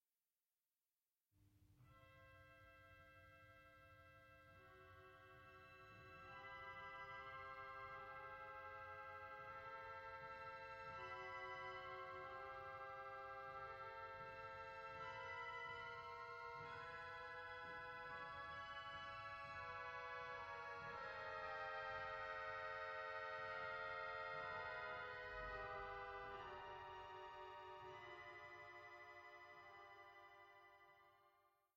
Große Orgel (Improvisationen)